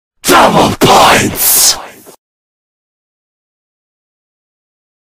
Play, download and share Double Poits original sound button!!!!
call-of-duty-world-at-war-nazi-zombies-double-points-sound-effect.mp3